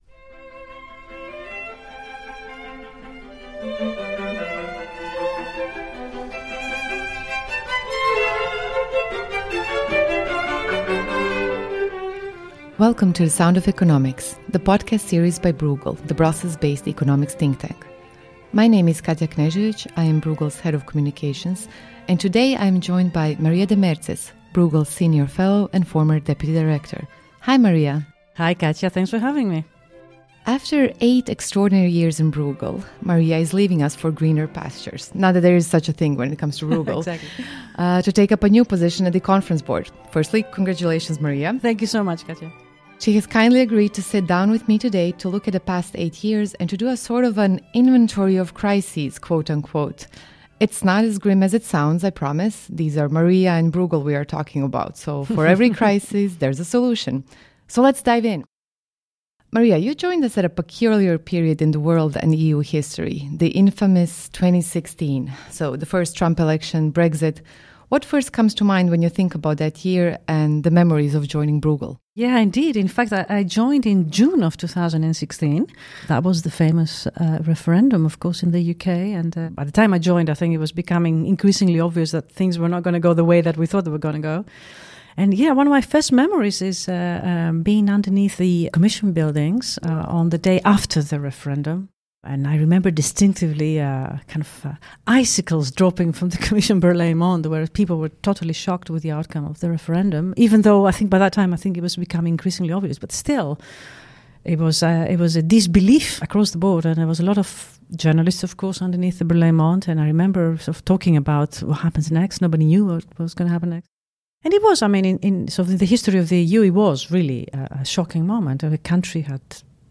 A fireside chat
sits down in the studio
interview